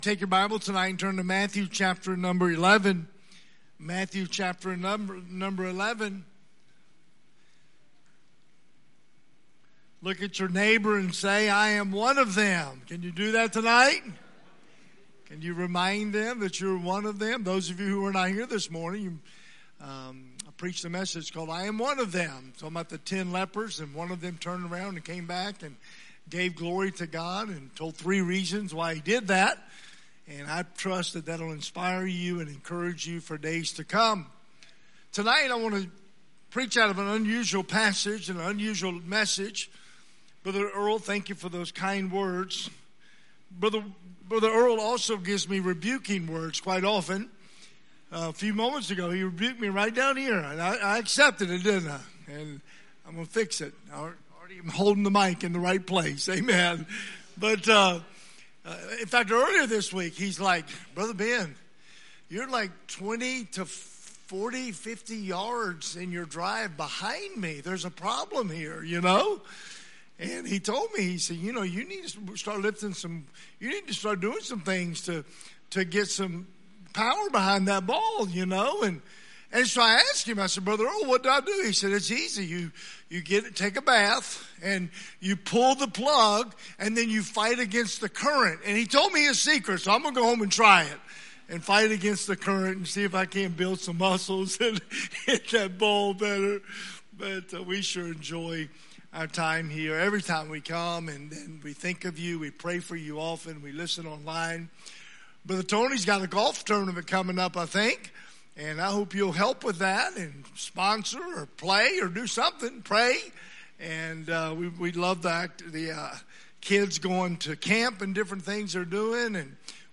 Sunday Evening
Sermons